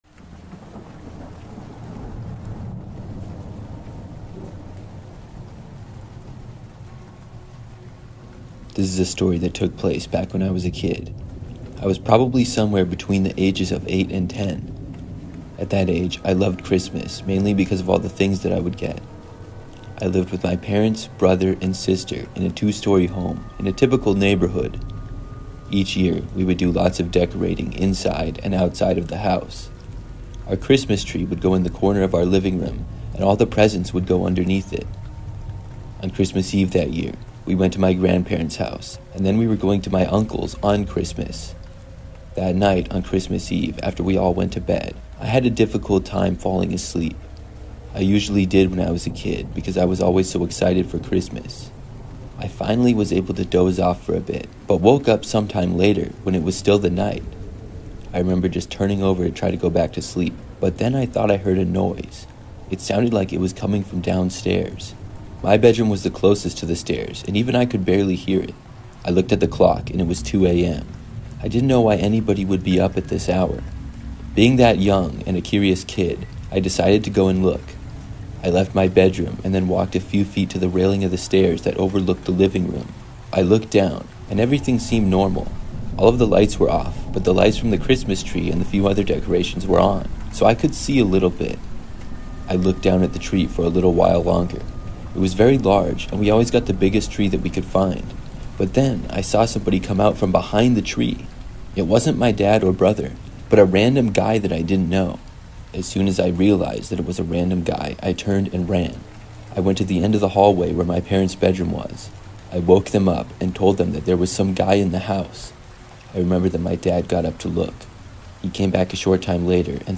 True Facebook Marketplace Horror Stories (With Rain Sounds) That Will Make You Question Transactions